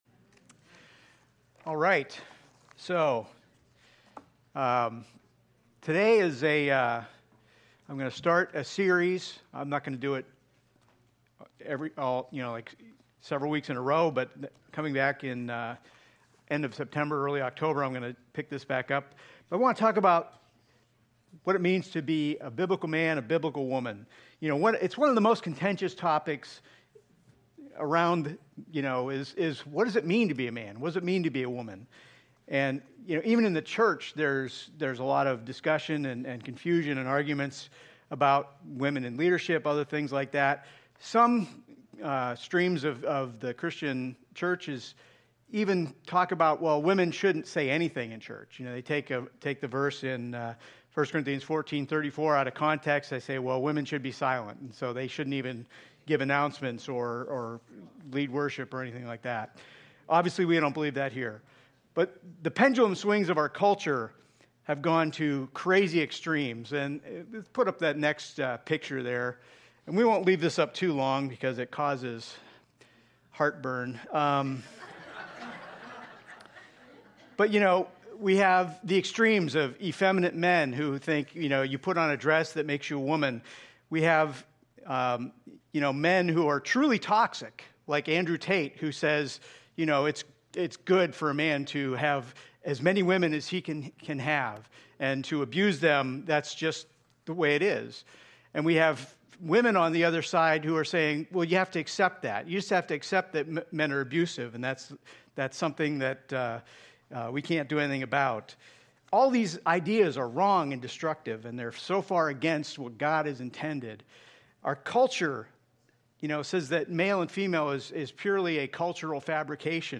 From Series: "Sunday Morning Service"